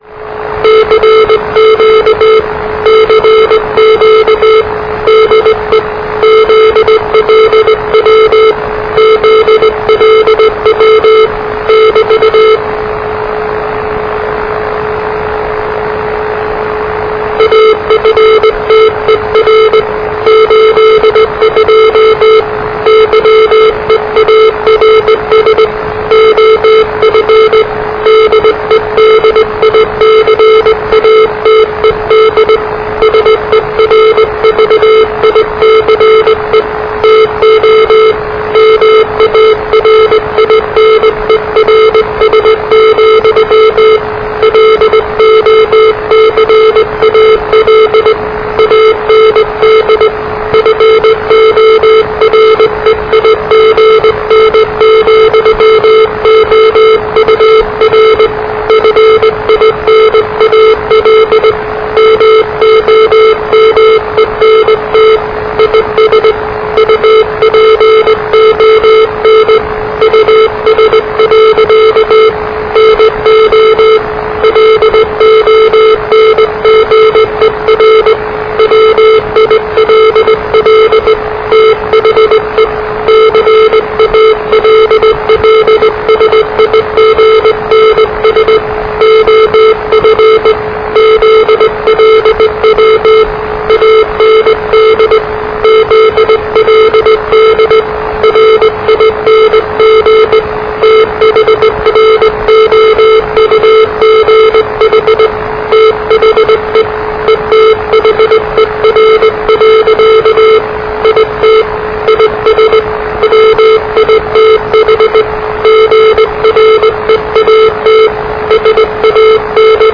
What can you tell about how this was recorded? Here’s a recording of Wellington Radio ZLW on 30 September 1993.